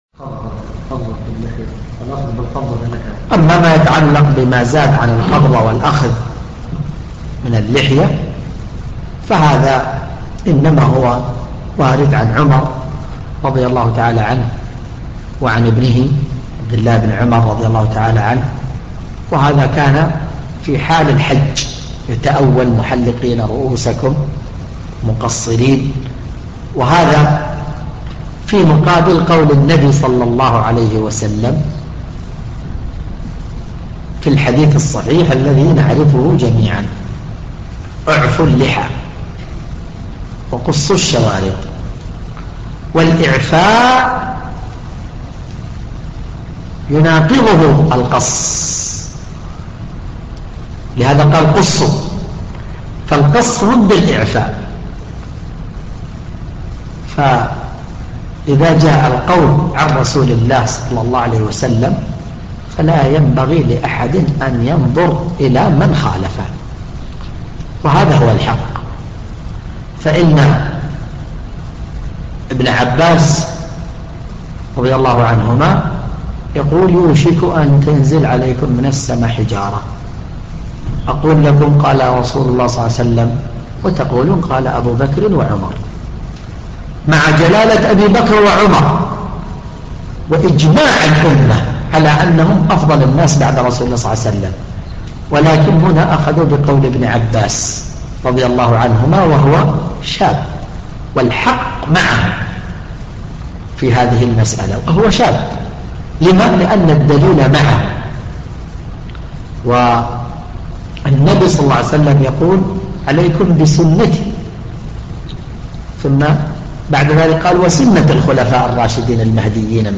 ملف الفتوي الصوتي عدد الملفات المرفوعه : 1